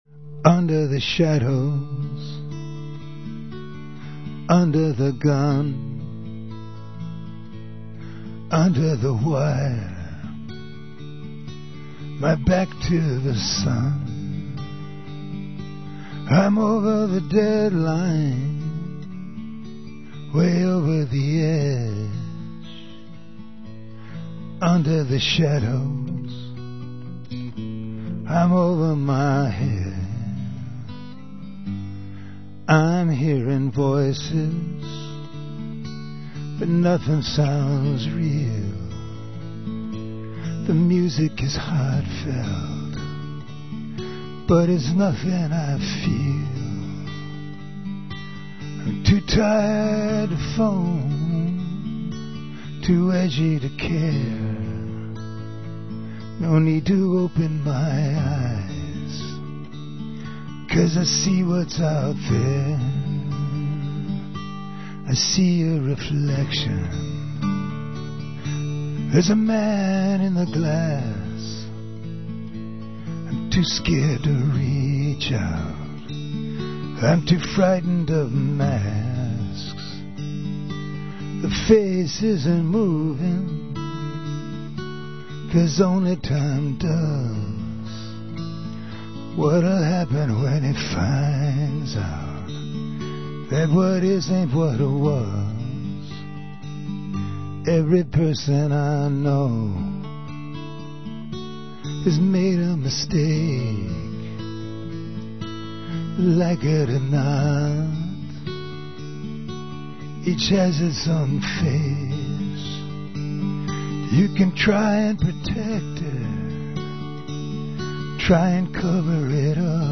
live songs (from radio)
mono